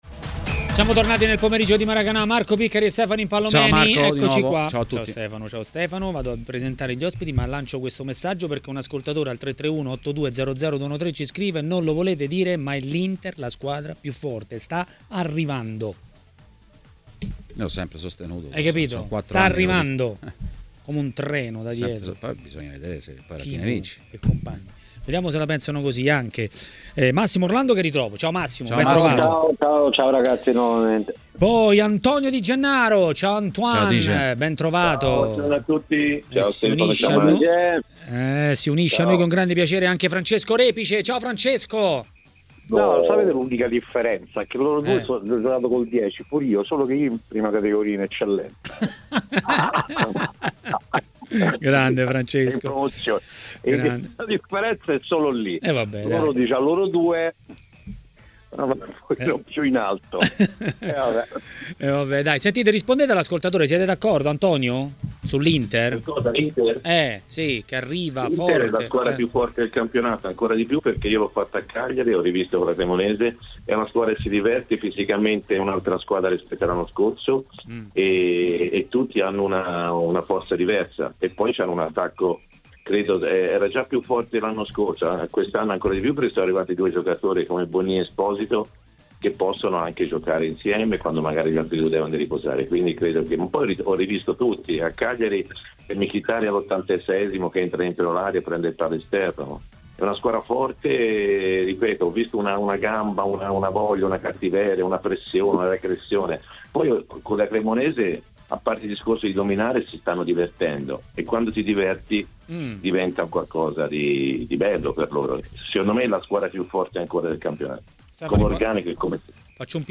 Antonio Di Gennaro, ex calciatore e commentatore tv, ha parlato dei temi del giorno a TMW Radio, durante Maracanà.